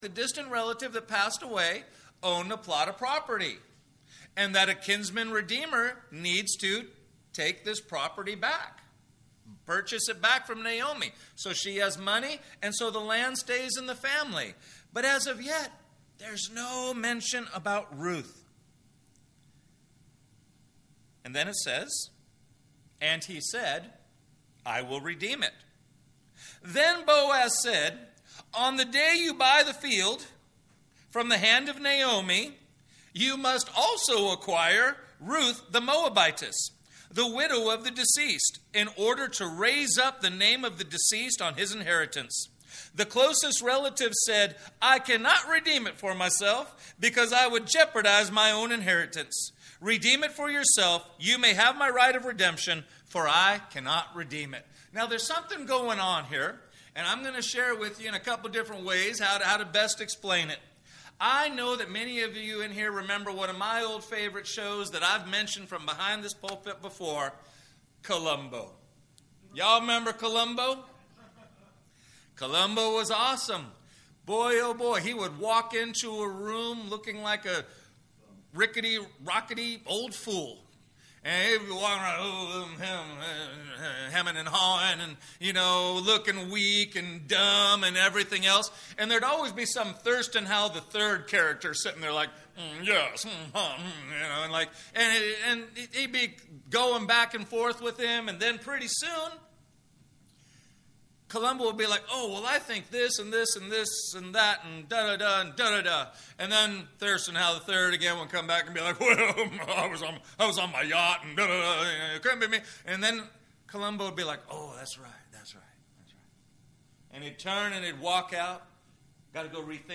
Note: First 20 seconds of recording are silent